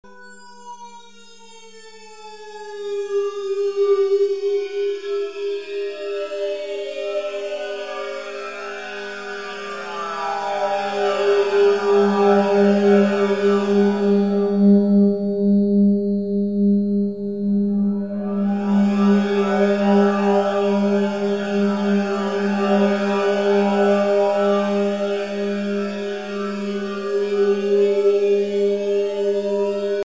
This page contains some example sounds produced by Tao instruments.
a bizarre stringed instrument with an unusual excitation
• A sinusoidal signal is used to move an access point up and down the length of the string at audio rate.
• All the sinusoidal signals start off with the same phase but they are each set to slightly different frequencies so that they drift out of phase.
As with many of the other examples on this page the left and right channels of the stereo output signal are derived from points at either end of this resonator.